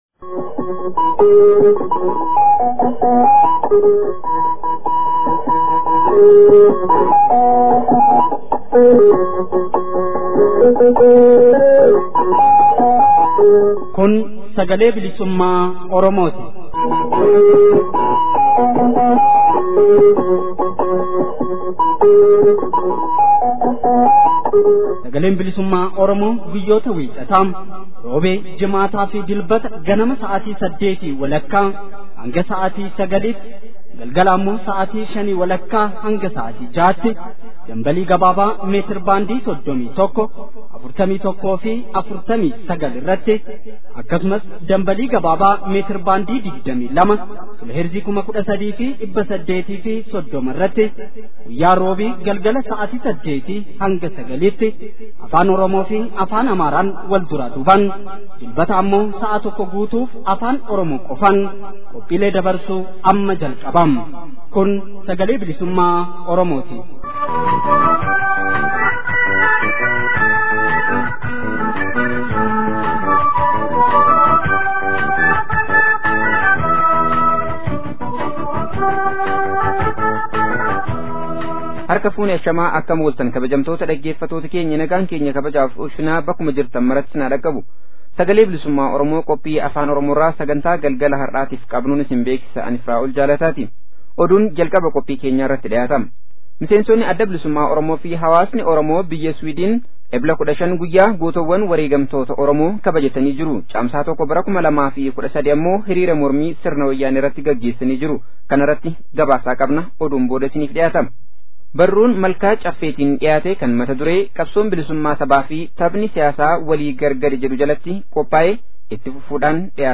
Qeerroon Bilisummaa naannoo Finfinnee jiraatan Irreecha Arfaasaa ilaalchisuun Caamsaa 07,2013 walitti dhufuun diddaa fi mormii sirna Wayyaaneef qaban walleen dhageessisan. Wallee warraaqsaa